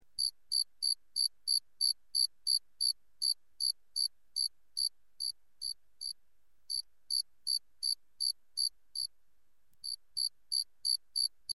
催眠音-试听
sleep-6.mp3